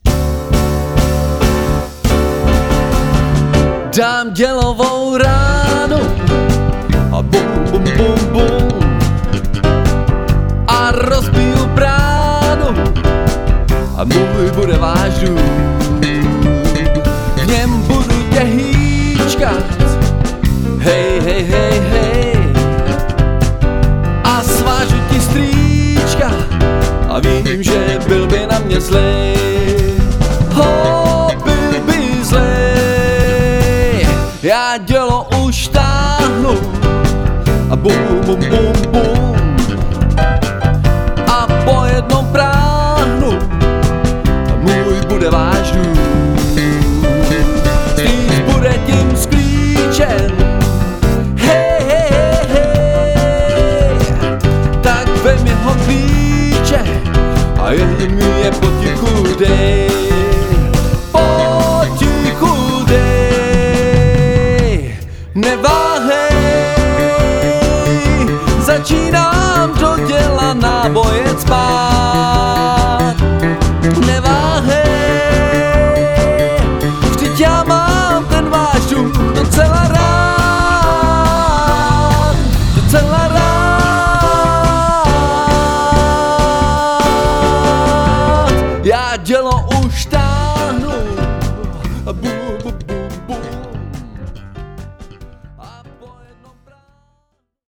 Známé POPové pecky